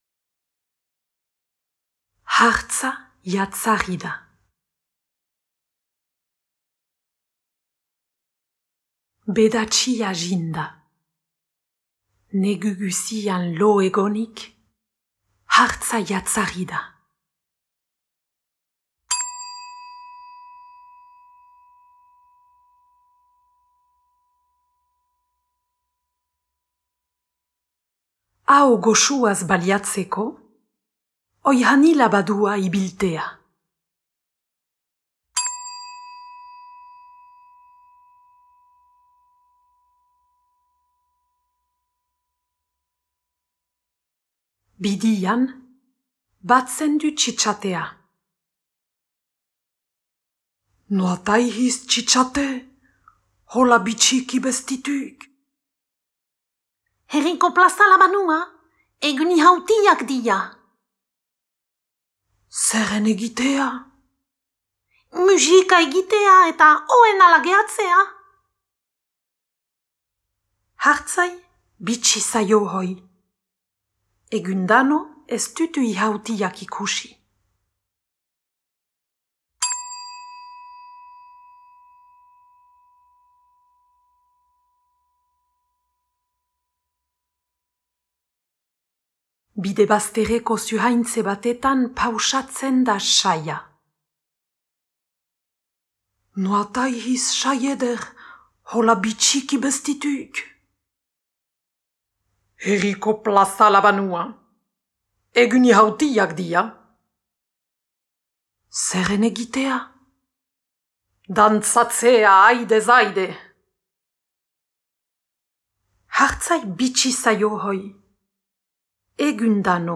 Hartza iratzarri da - zubereraz